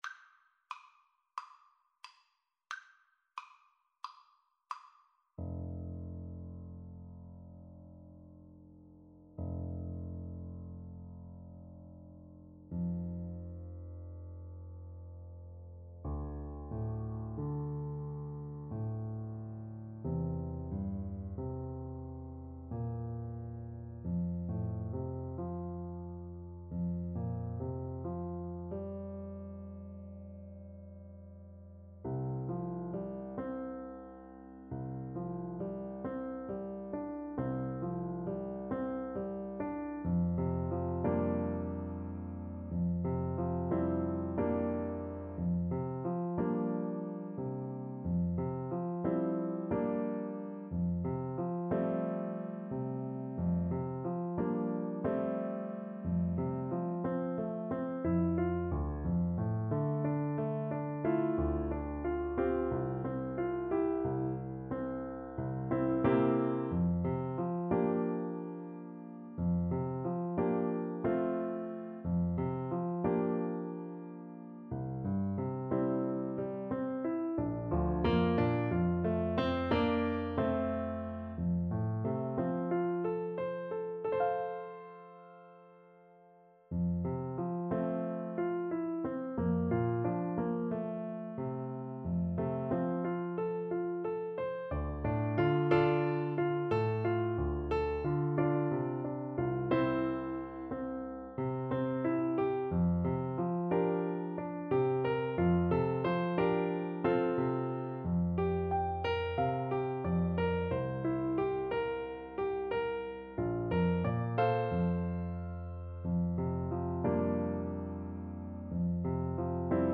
4/4 (View more 4/4 Music)
Andante cantabile = c. 90